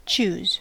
Ääntäminen
Ääntäminen US : IPA : [tʃuz]